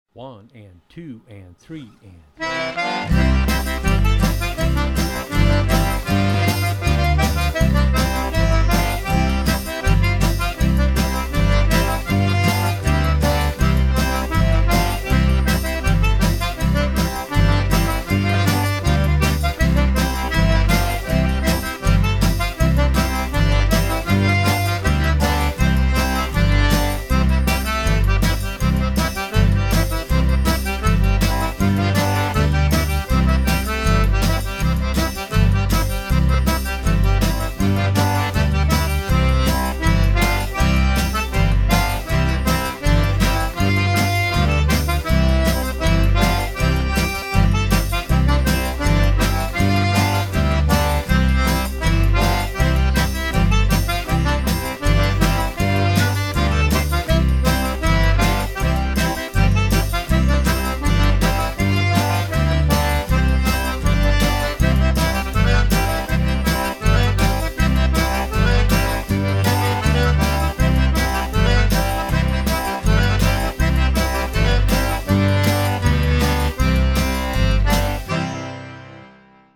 When first learning the Cajun accordion, you may be better off learning one hand at a time.
Here is an audio sample of the Walfus Two Step with accompaniment.